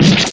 penxue.mp3